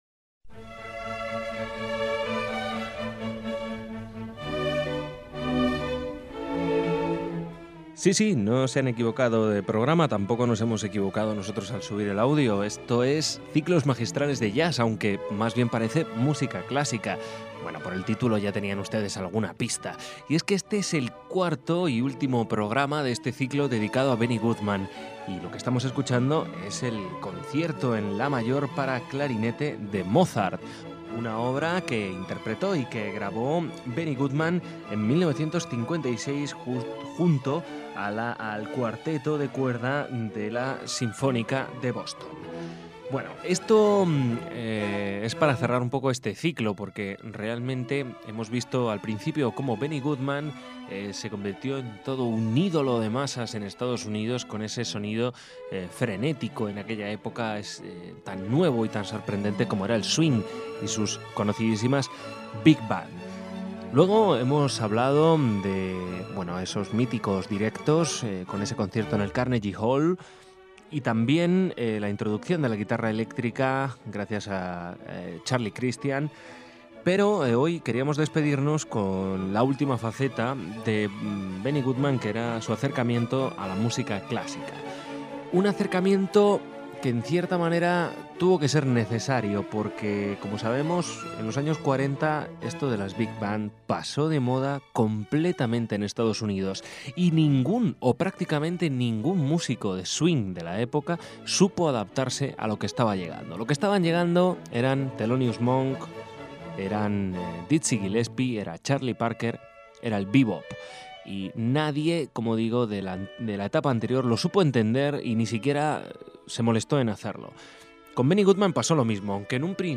Benny Goodman (IV): el concierto para clarinete en la mayor… de Mozart